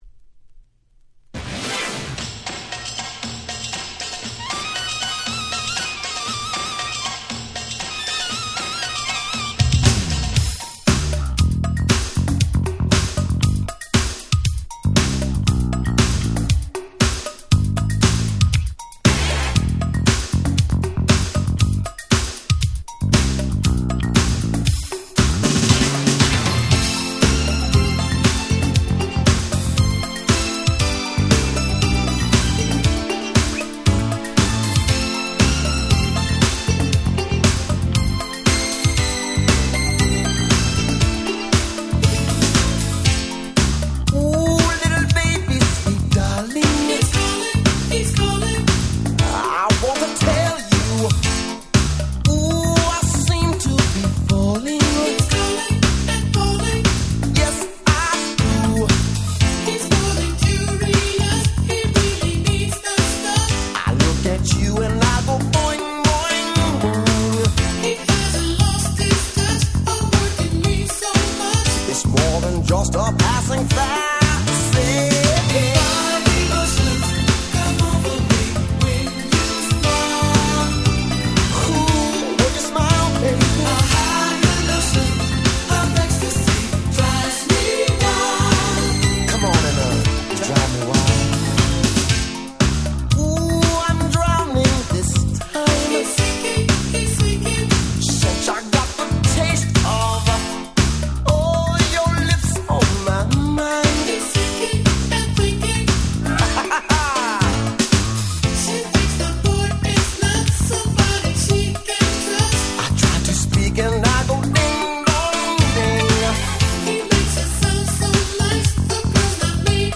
88' Smash Hit R&B / New Jack Swing !!
キラキラしたシンセの音色が心地良い爽快ダンスナンバー！！
NJS ニュージャックスウィング